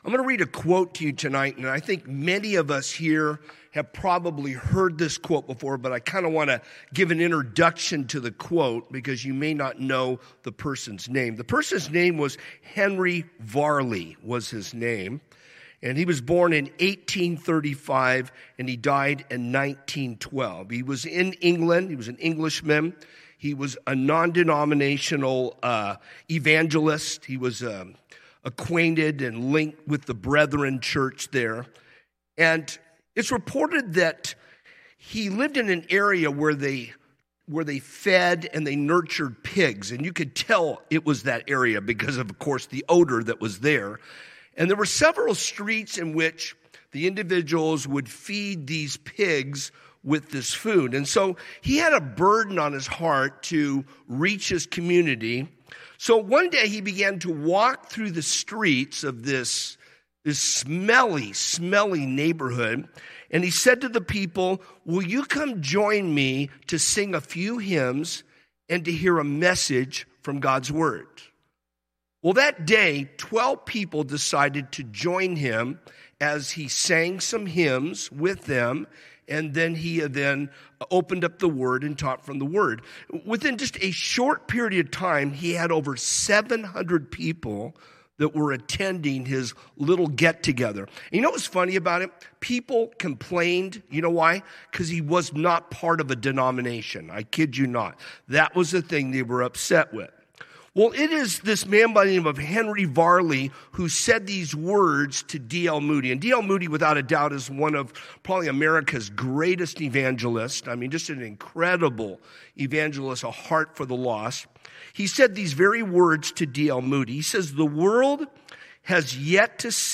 A message from the series "God At Work."
calvary_chapel_rialto_livestream-240p-online-audio-converter.com_.mp3